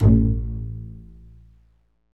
Index of /90_sSampleCDs/Roland LCDP13 String Sections/STR_Cbs FX/STR_Cbs Pizz